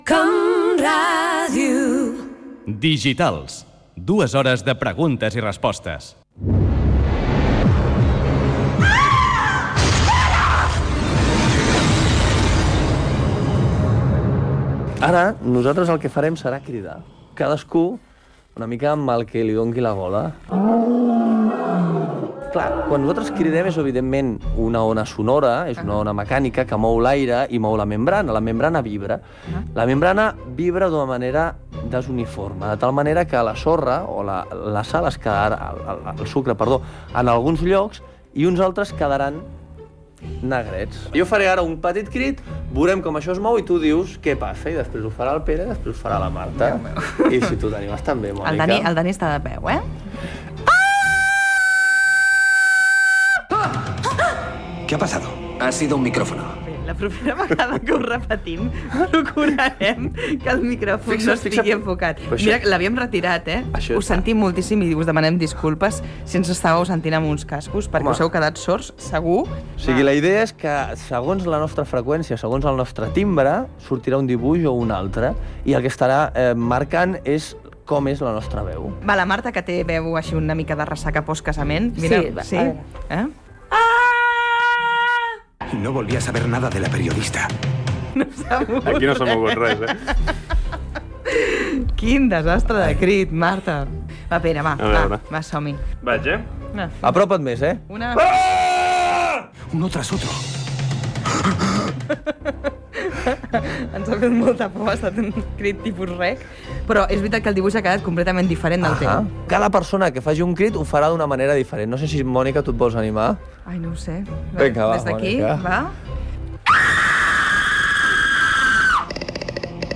Entreteniment
FM
Fragment extret de l'arxiu sonor de COM Ràdio.